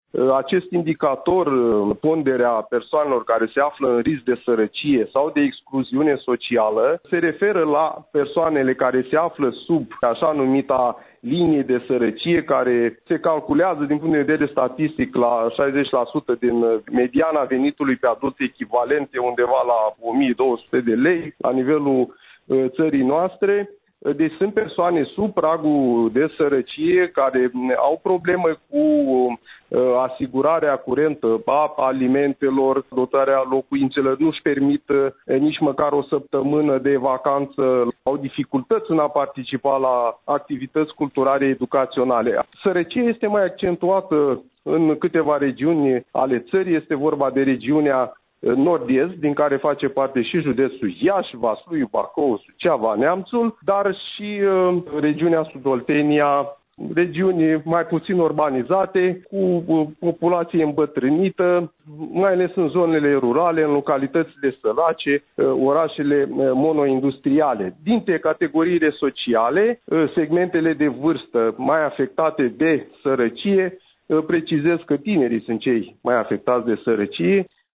Într-o intervenție pentru postul nostru de radio